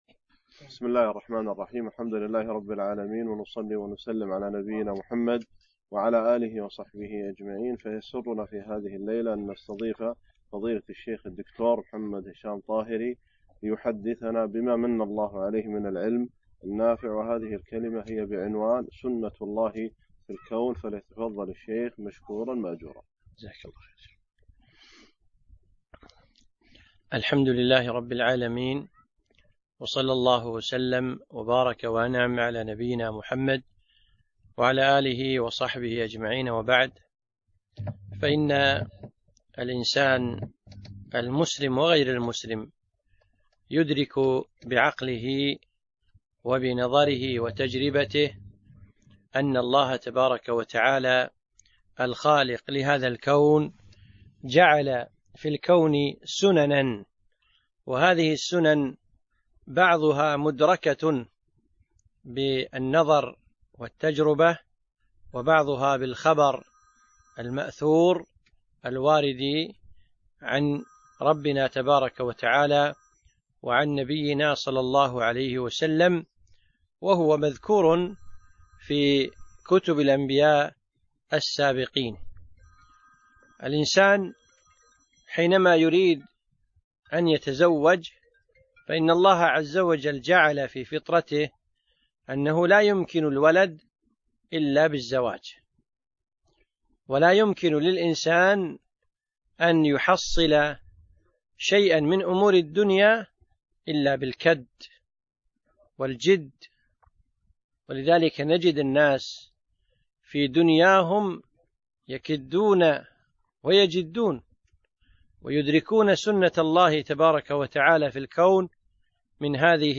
محاضرة سنة الله في الكون